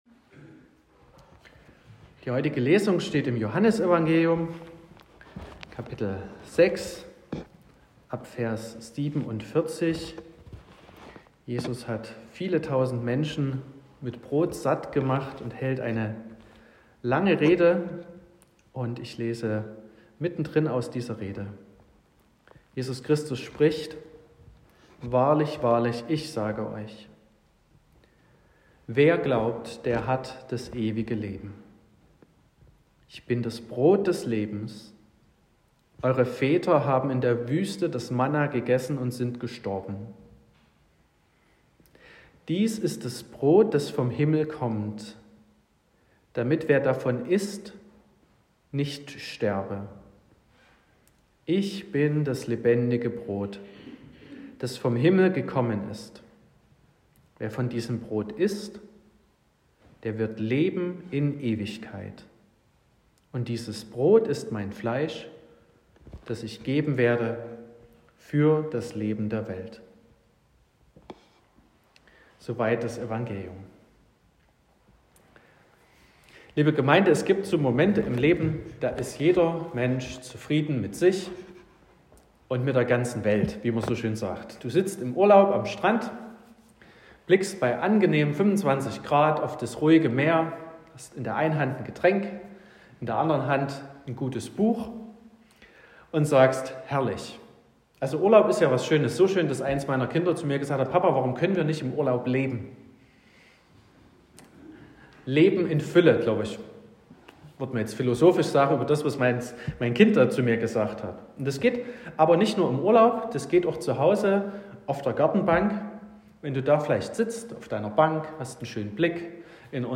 30.03.2025 – Gottesdienst
Predigt (Audio): 2025-03-30_Wahres_Leben_finden.m4a (8,7 MB)